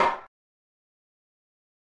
Female voice or vocal saying party people how are you feeling.